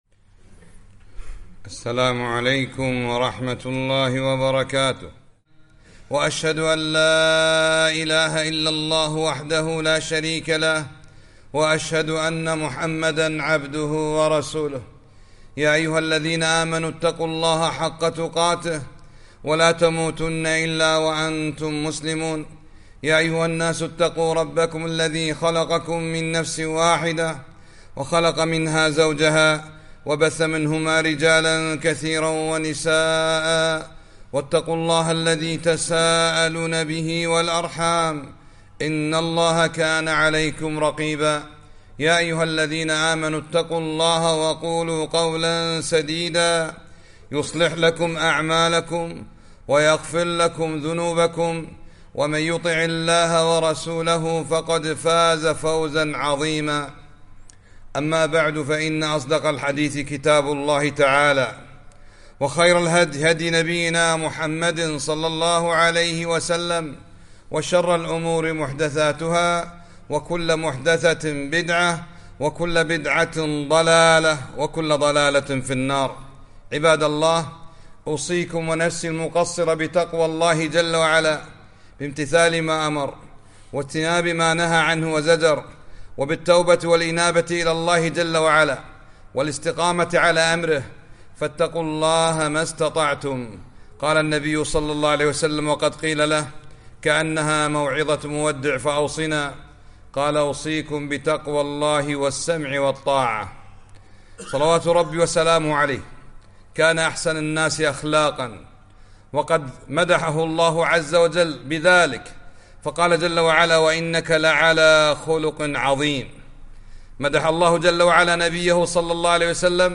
خطبة - حسن الخلق